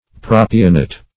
propionate - definition of propionate - synonyms, pronunciation, spelling from Free Dictionary Search Result for " propionate" : The Collaborative International Dictionary of English v.0.48: Propionate \Pro"pi*o*nate\, n. (Chem.) A salt of propionic acid.